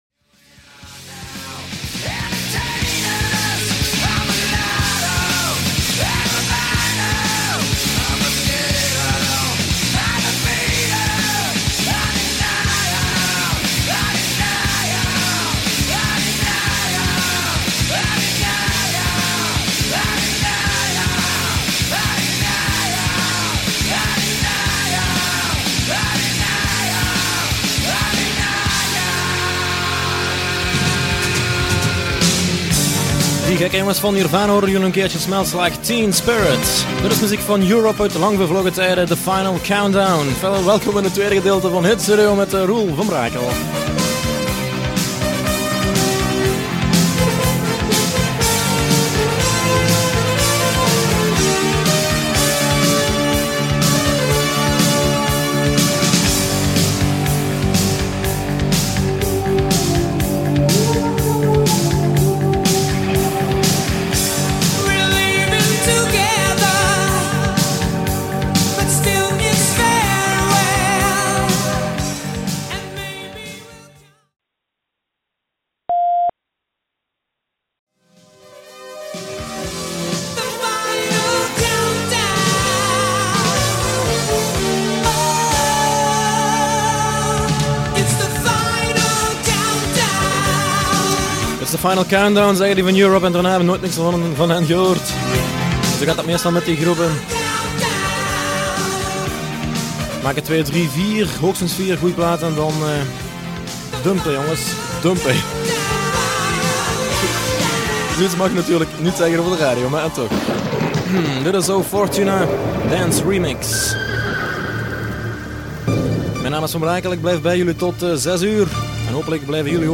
Hier enkele uitzendingen.
Veel van de geluidsfragmenten op deze website zijn afkomstig van cassettebandjes (muziekcassettes).